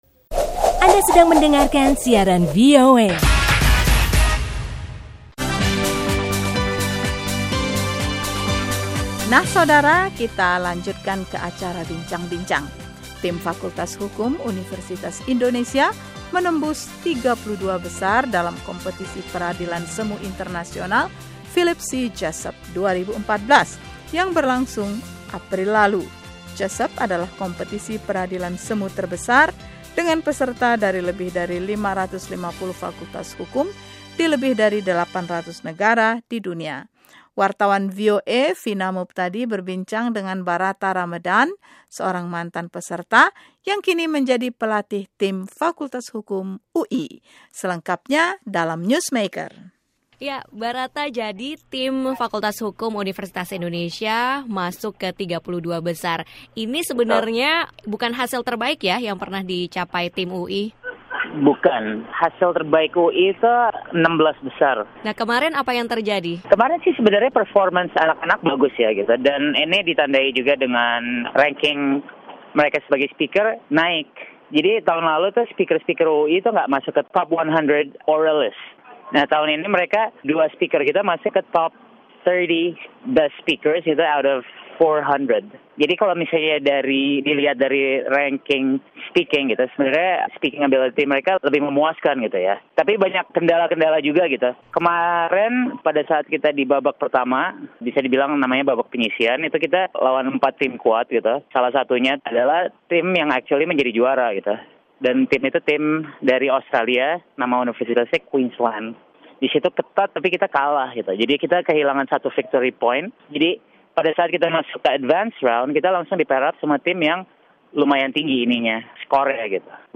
Bincang-bincang